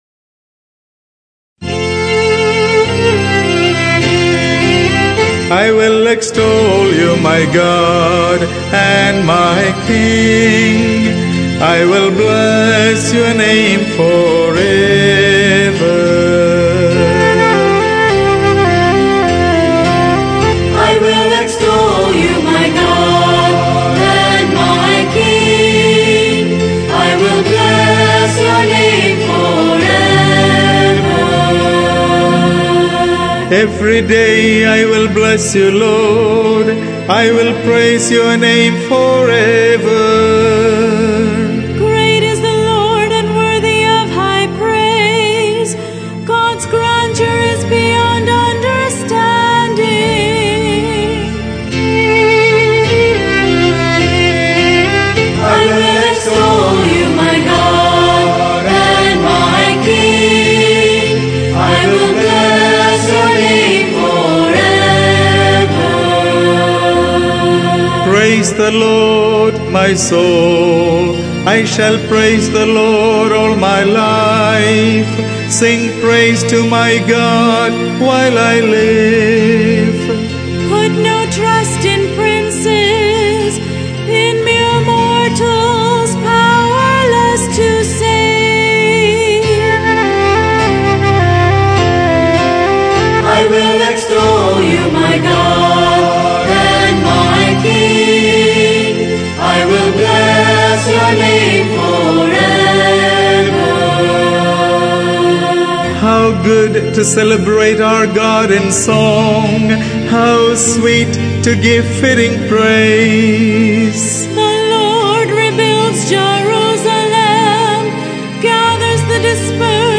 and chorus